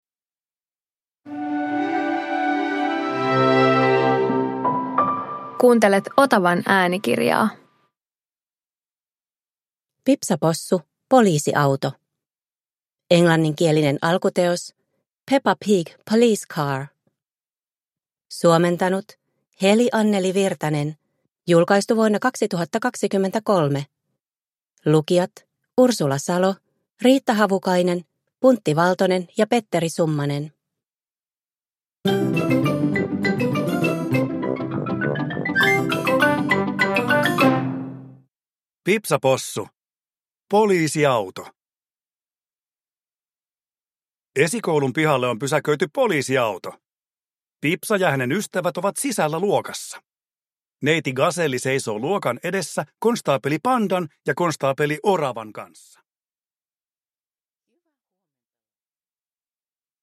Pipsa Possu - Poliisiauto – Ljudbok